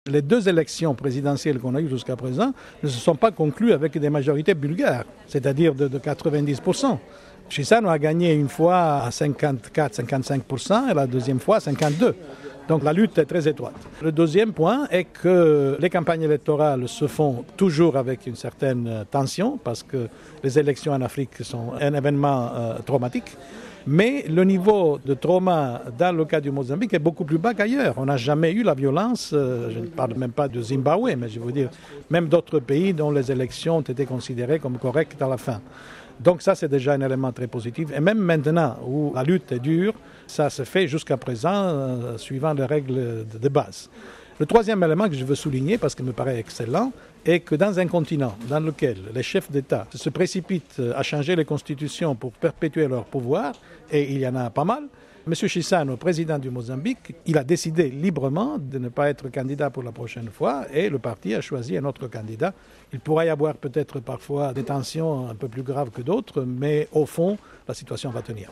Ecoutez l’analyse d’Aldo Ajello, représentant spécial de l’Onu chargé de la pacification du pays en 1992.